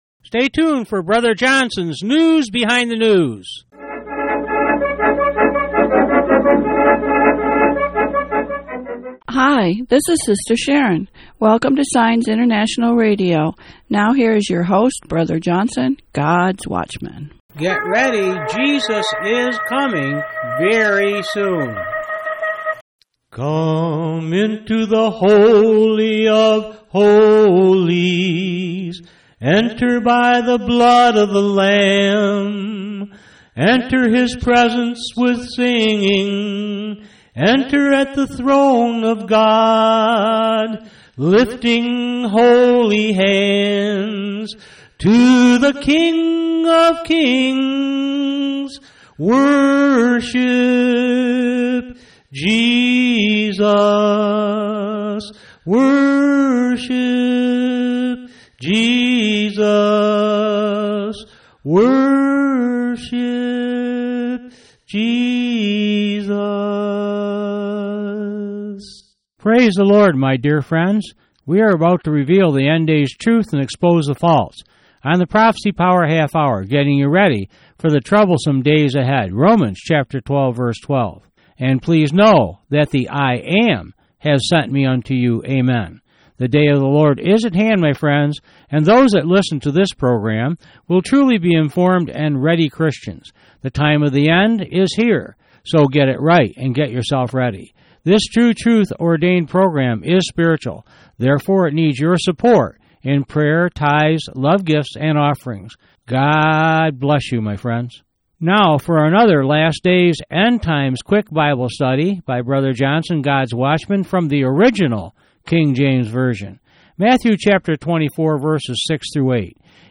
Message Details: WEDNESDAY October 17, 2012 Prophecy Power half hour news